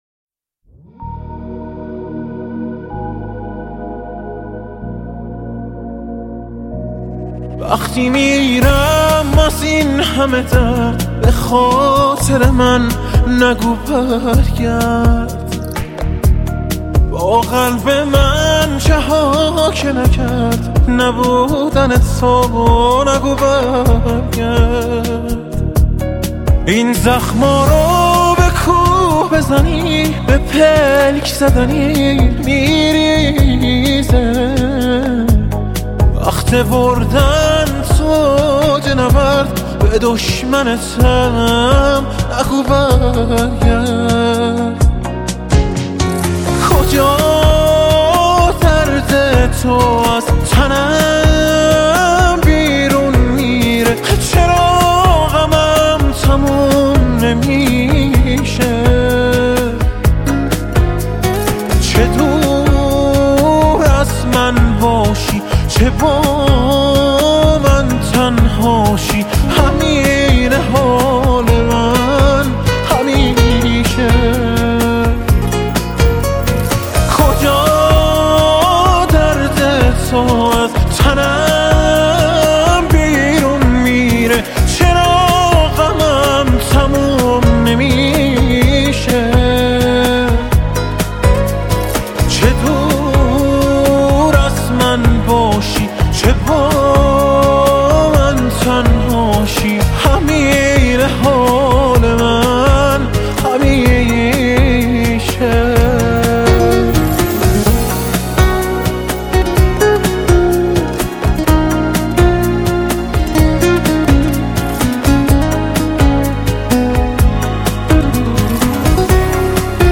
با ریتم 2/4